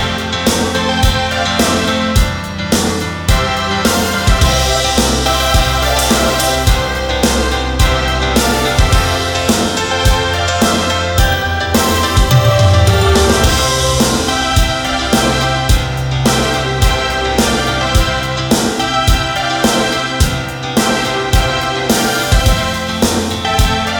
Irish Backing Tracks for St Patrick's Day